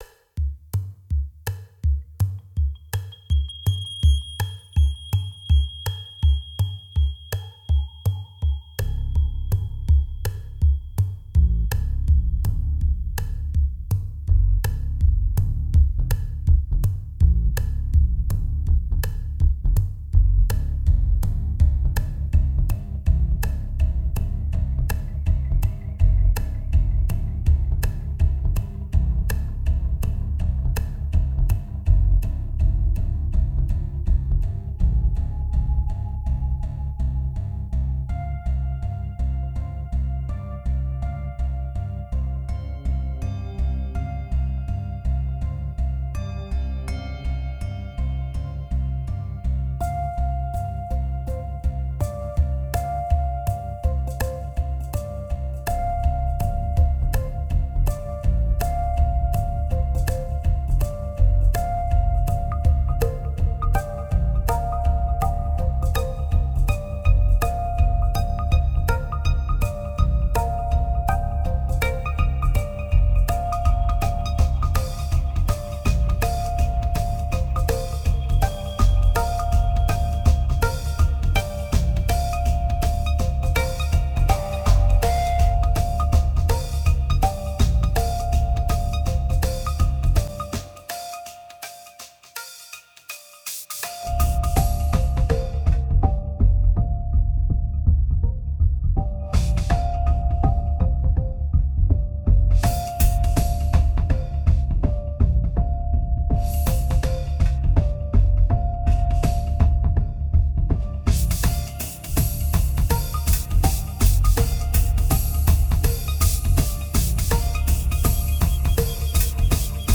1767📈 - -51%🤔 - 82BPM🔊 - 2010-04-18📅 - -346🌟